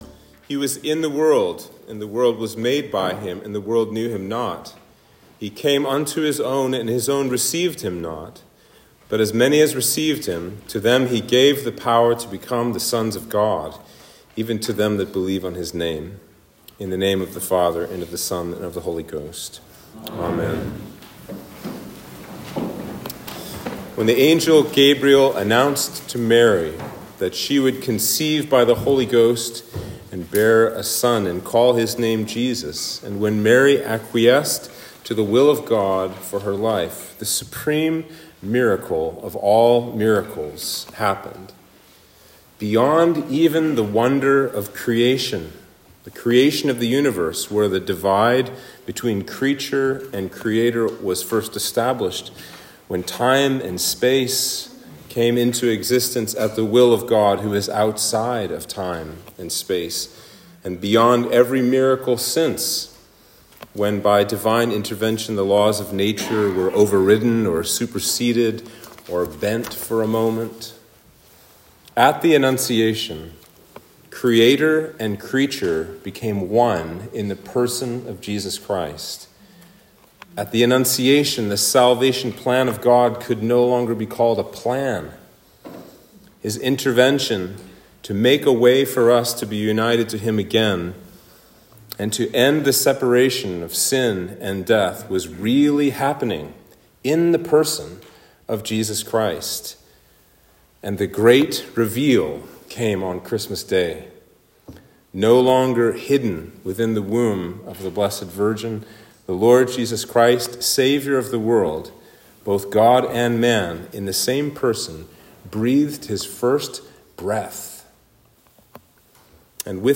Sermon for Christmas Day